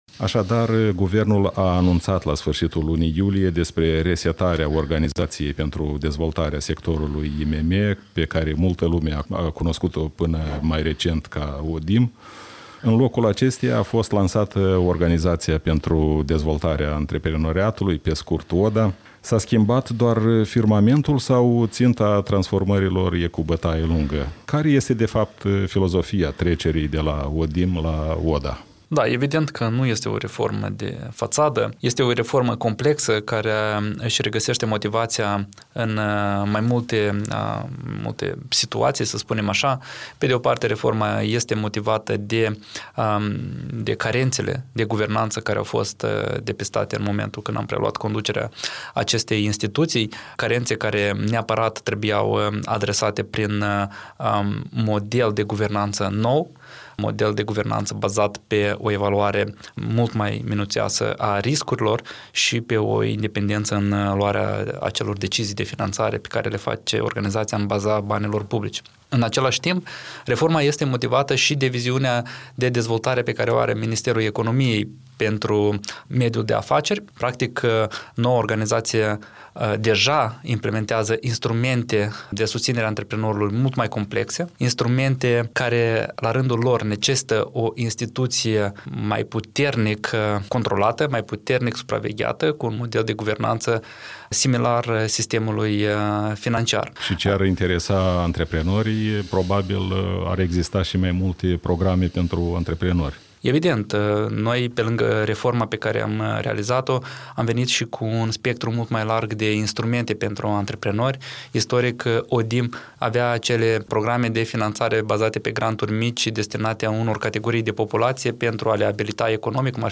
Interviu cu Dumitru Pîntea, directorul interimar al Organizației pentru Dezvoltarea Antreprenoriatului